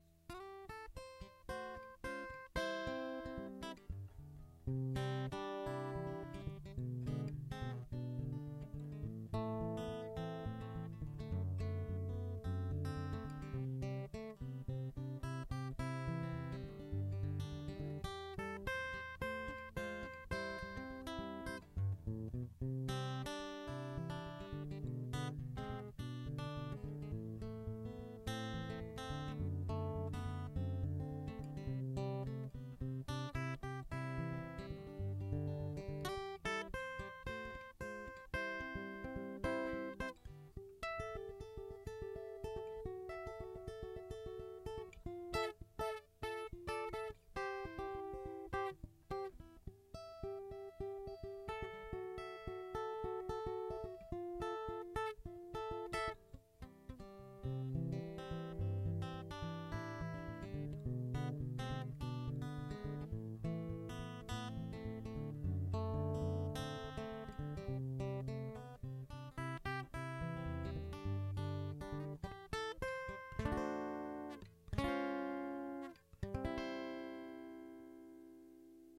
Guitar Mic Comparisons
In this first clip – the guitar is played with a pick and is using the pick up through a DI.